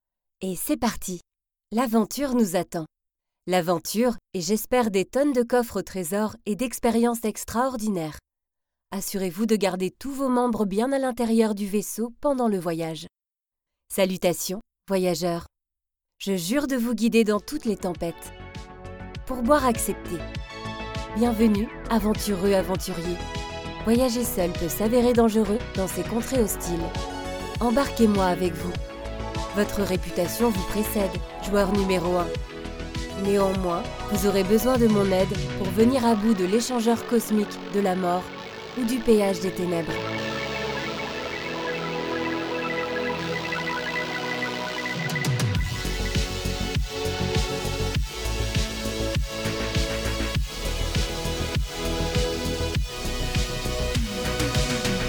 Jeune, Naturelle, Douce, Chaude, Commerciale
Avec une voix moyenne, chaude et fluide, je donne vie à vos projets grâce à des narrations authentiques, captivantes et empreintes de sincérité.
Mon studio d’enregistrement professionnel garantit une qualité sonore optimale pour vos productions.